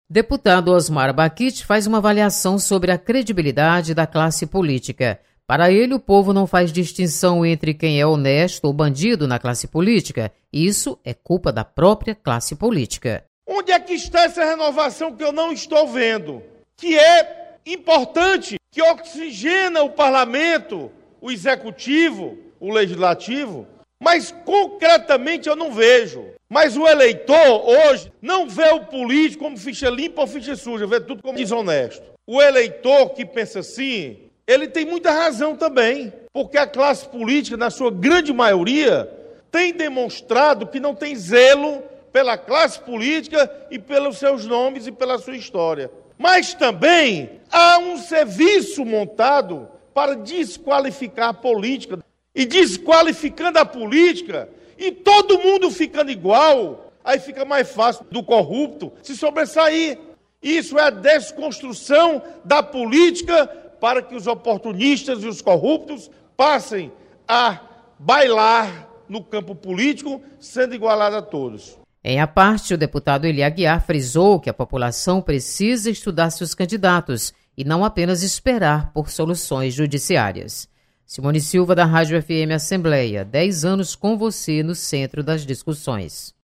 Osmar Baquit chama atenção para credibilidade política. Repórter